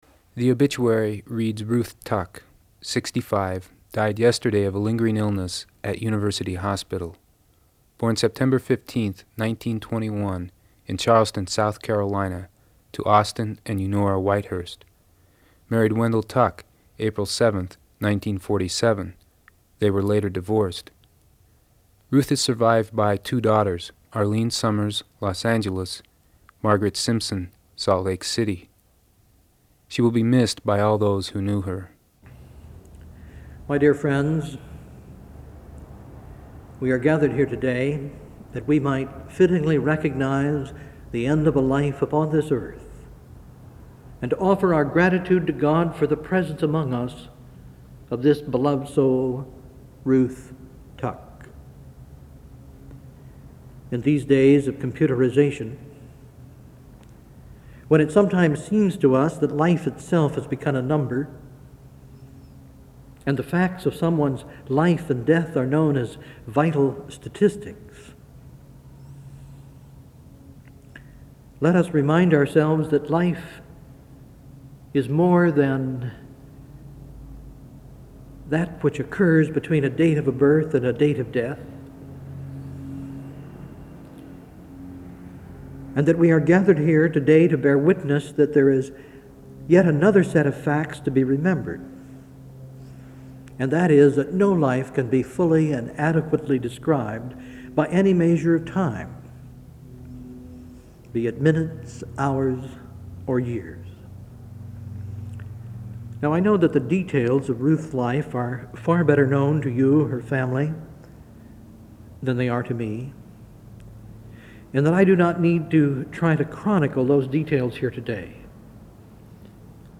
RSS 🧾 Download transcript Summary A radio play about how we, as a culture, deal with death and dying.